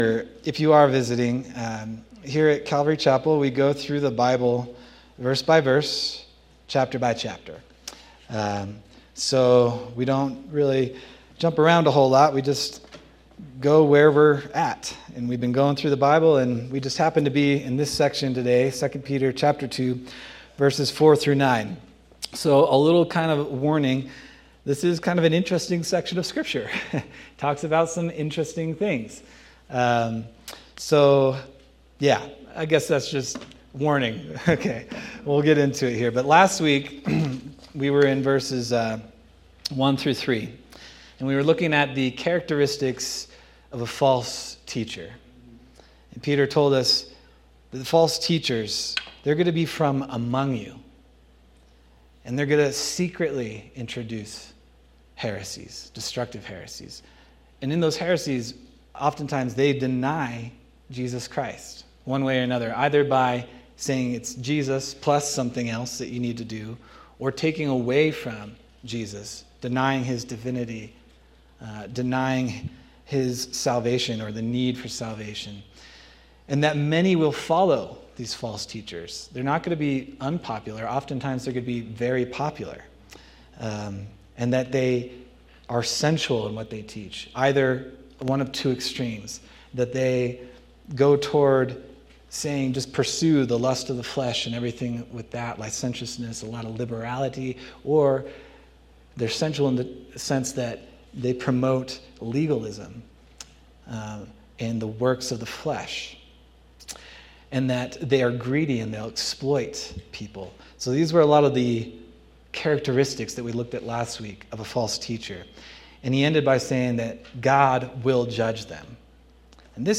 October 26, 2025 Sermon